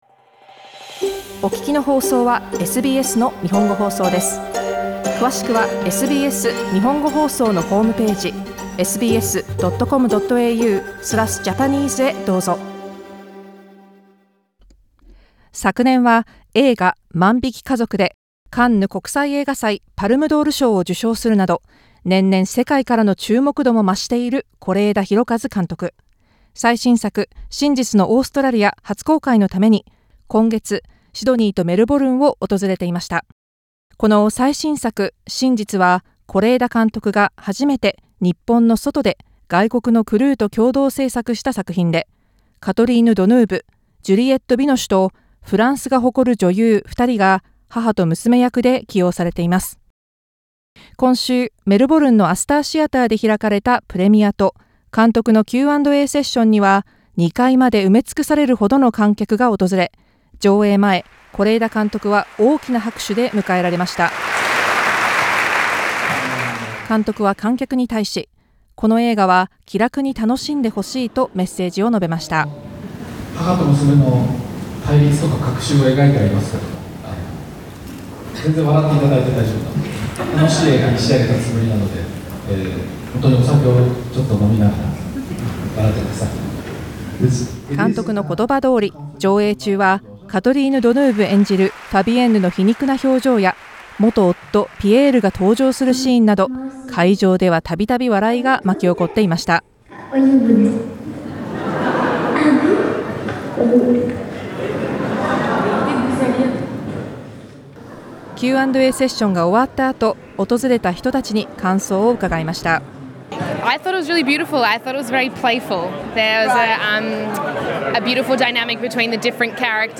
メルボルンの先行上映の様子と観客の声をリポートでお届けします。